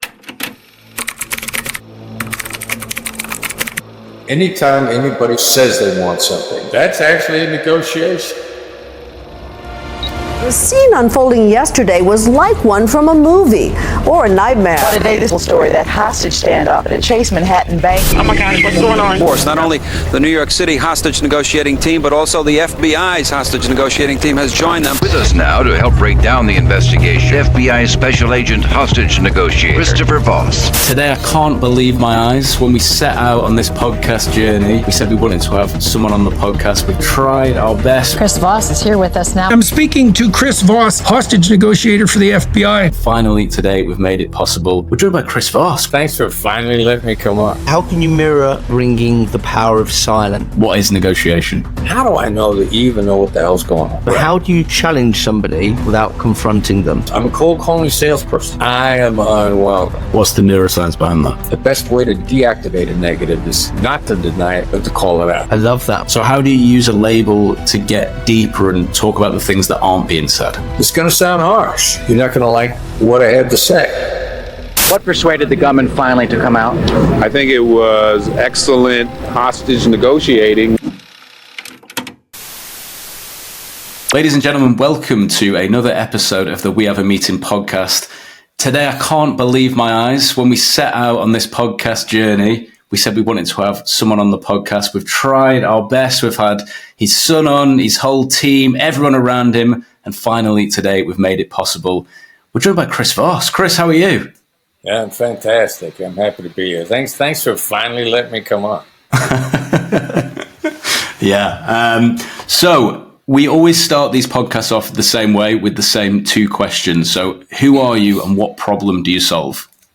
Join us for an exclusive conversation with Chris Voss, renowned former FBI hostage negotiator and the author of the international bestseller 'Never Split the Difference'. Chris is one of the world's leading experts on negotiation, and in this episode, he shares his invaluable insights that can transform your approach to communication and collaboration.